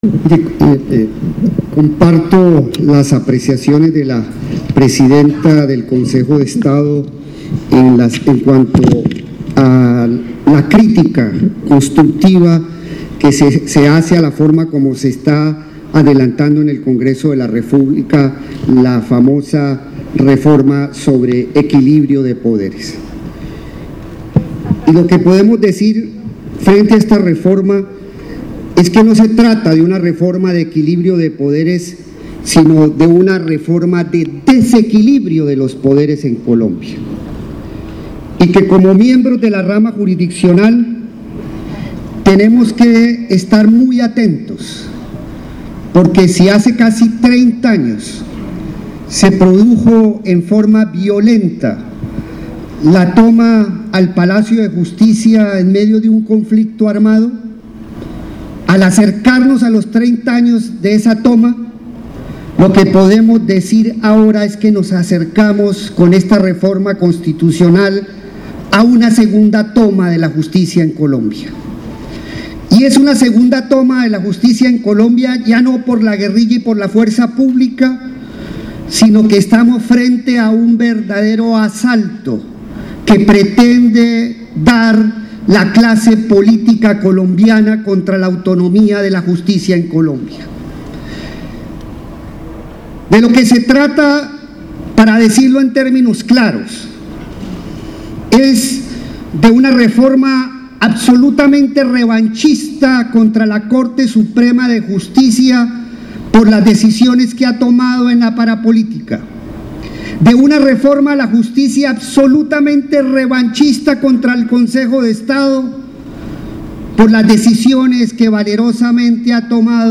Las declaraciones se dieron en la jornada de cierre del XX encuentro de la jurisdicción contencioso administrativa en Cartagena (Bolívar).
discurso-reformaquedesequilibra-14.mp3